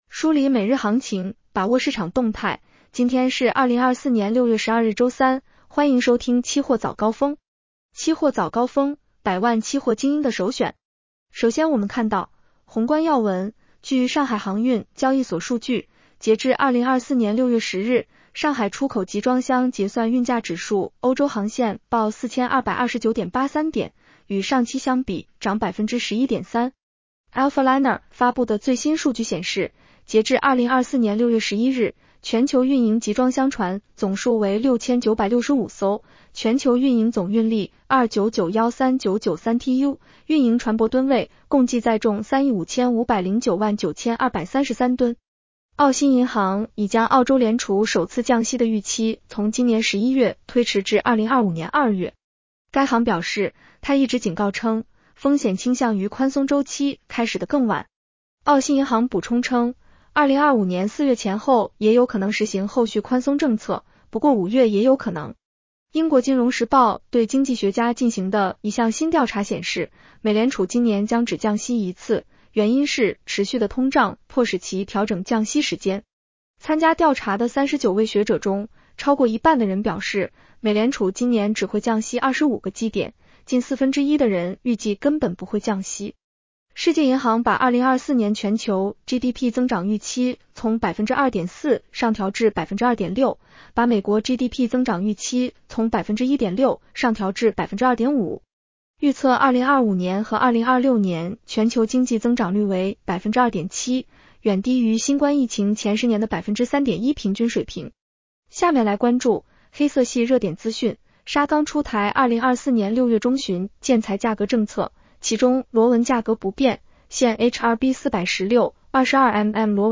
期货早高峰-音频版 女声普通话版 下载mp3 宏观要闻 1.据上海航运交易所数据，截至2024年6月10日， 上海出口集装箱结算运价指数（欧洲航线）报4229.83点，与上期相比涨11.3% 。